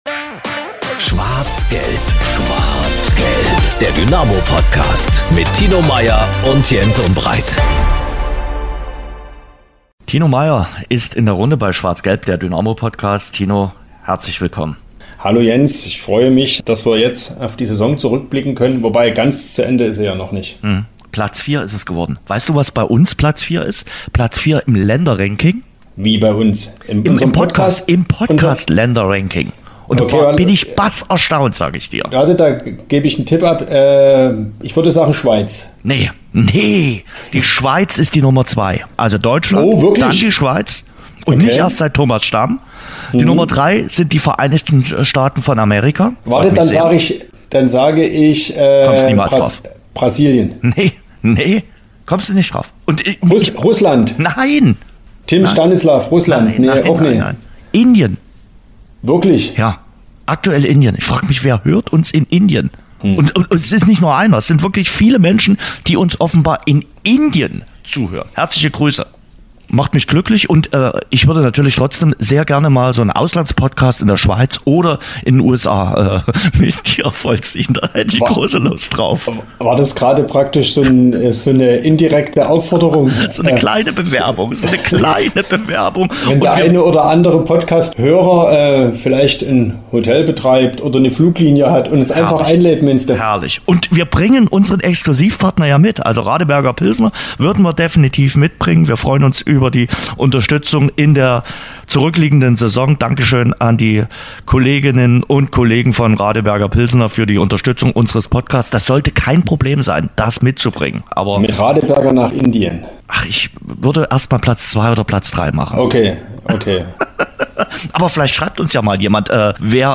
diskutieren mit den Sportjournalisten